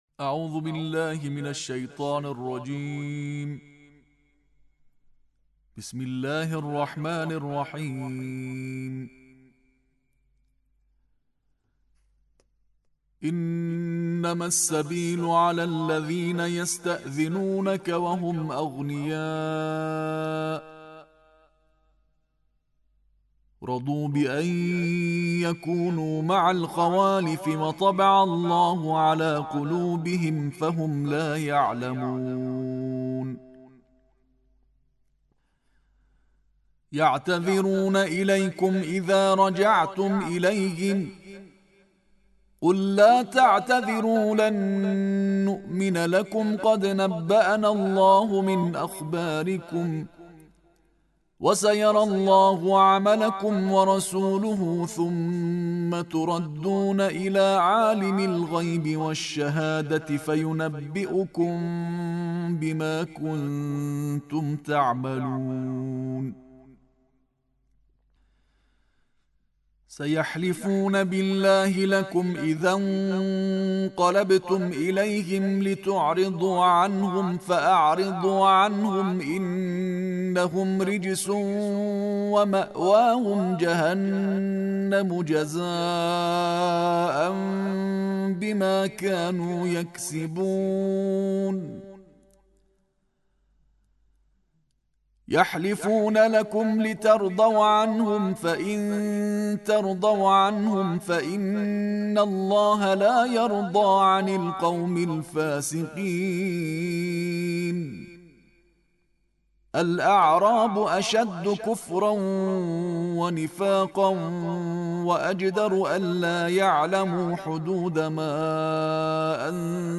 Тиловати тартили ҷузъи ёздаҳуми Қуръон